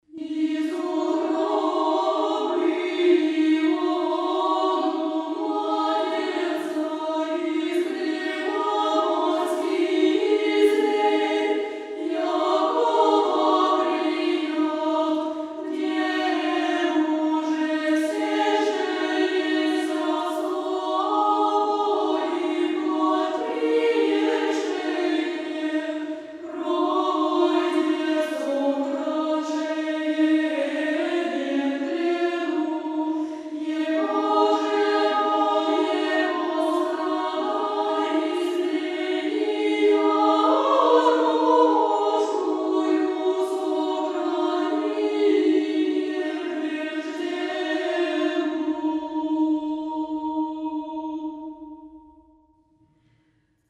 Канон Рождества Христова 6.mp3